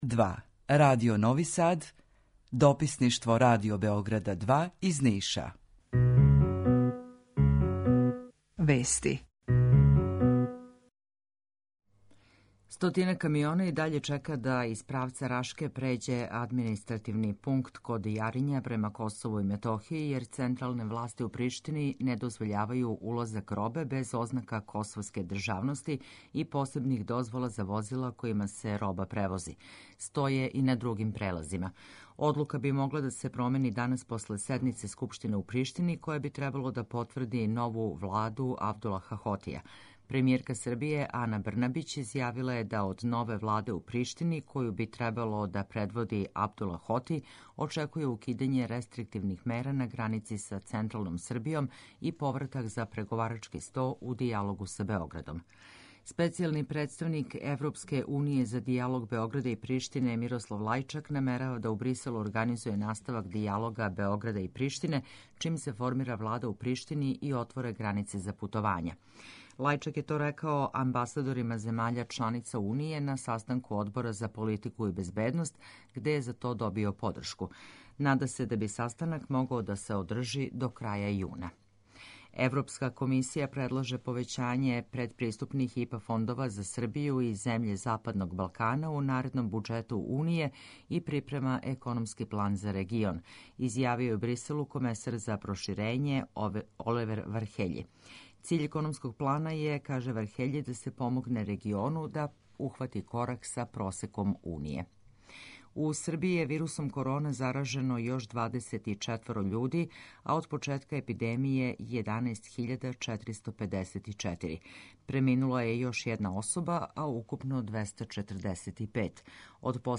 Укључење Радио Грачанице
У два сата, ту је и добра музика, другачија у односу на остале радио-станице.